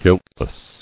[guilt·less]